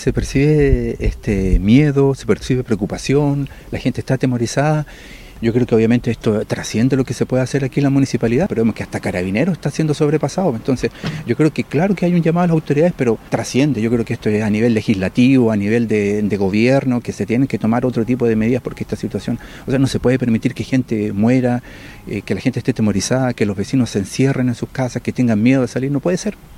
Uno de los vecinos considera que los habitantes de Máfil sienten miedo y están preocupados, por lo que hizo un llamado a la autoridad a tomar medidas a corto plazo.